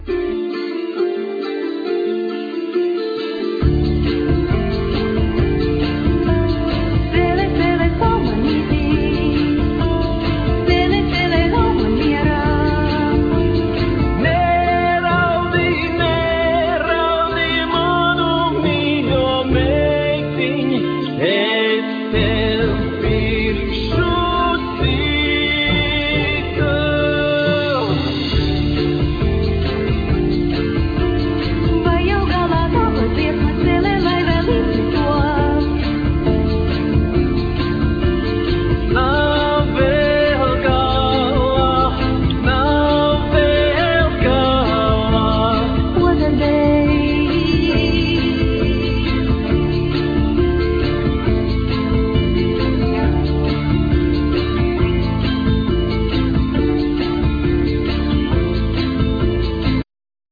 VocalViolin
Vocal,Kokle(box zither),Bagpipes,acordion,Keyboards
Guitar